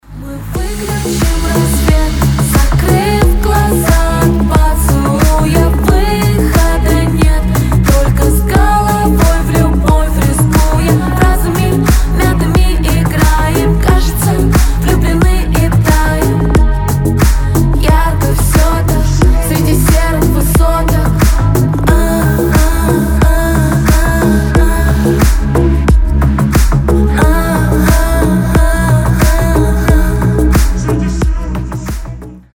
чувственные
медленные